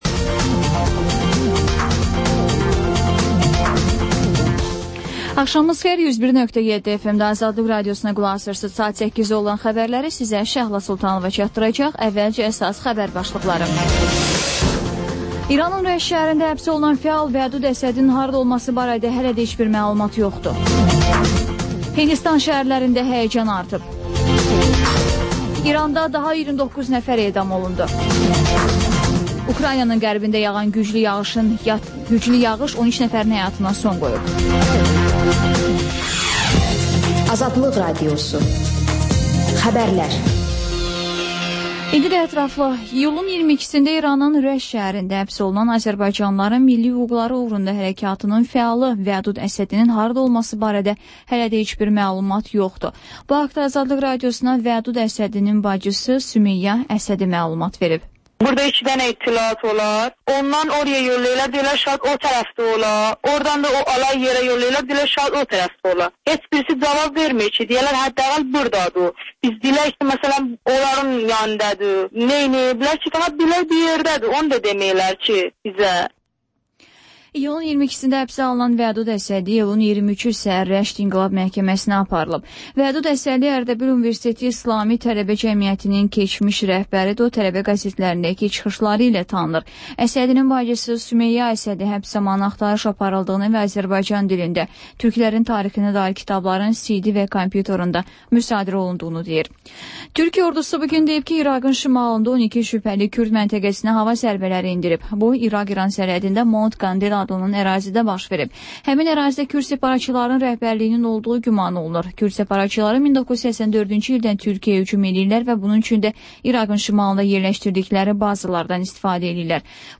Xəbərlər, İZ: Mədəniyyət proqramı və TANINMIŞLAR rubrikası: Ölkənin tanınmış simaları ilə söhbət